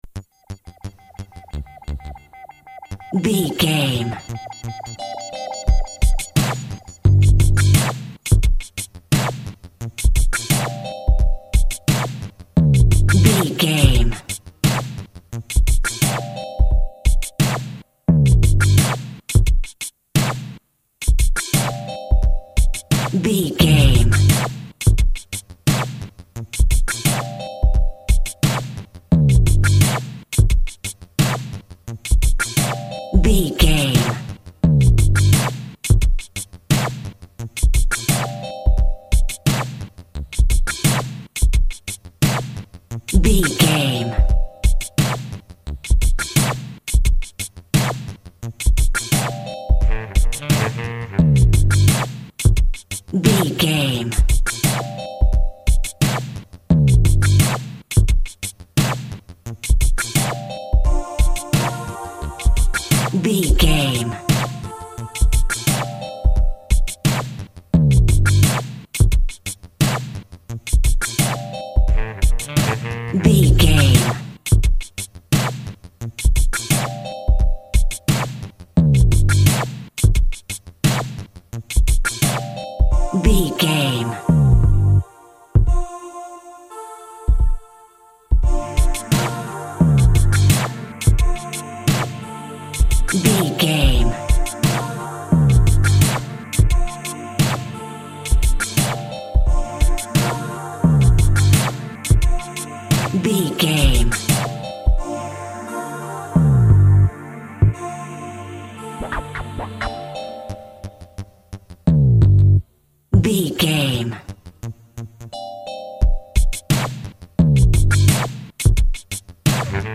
Hip Hop for Sadness.
Aeolian/Minor
synth lead
synth bass
hip hop synths
electronics